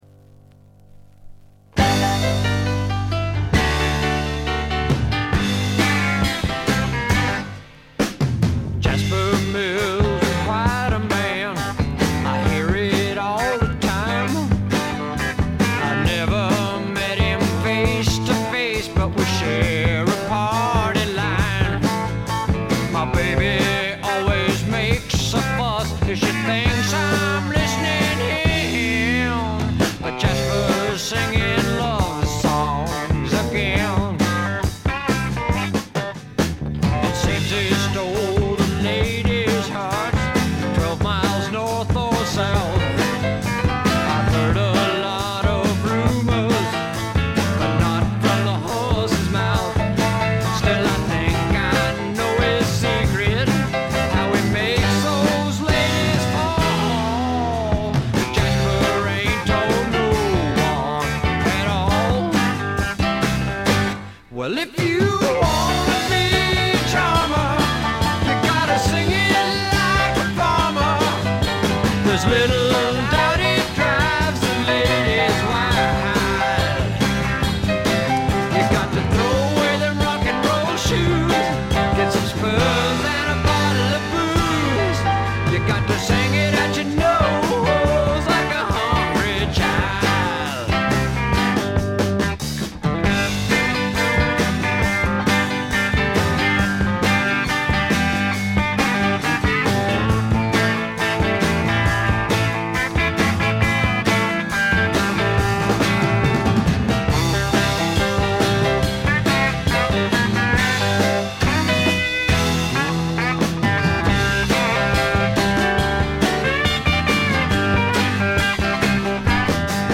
ほとんどノイズ感無し。
試聴曲は現品からの取り込み音源です。
Guitar and Vocals